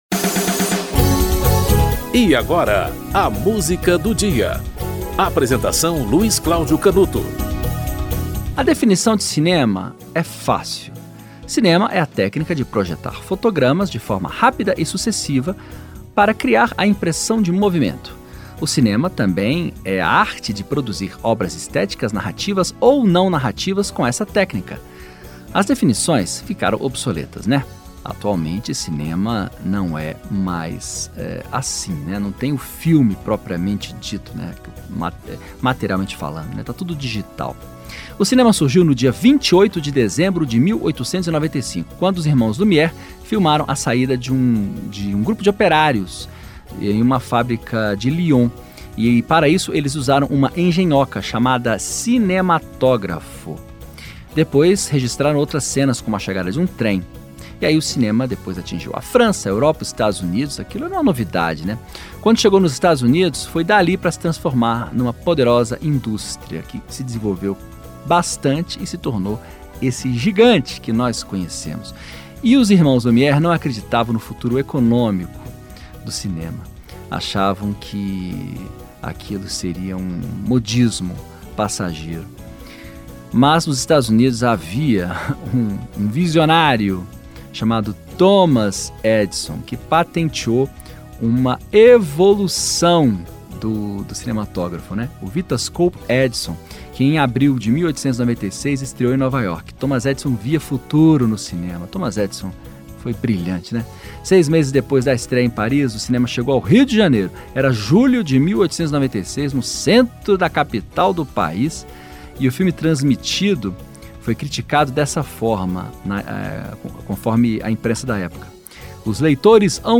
Rita Lee - Flagra (Rita Lee e Roberto de Carvalho)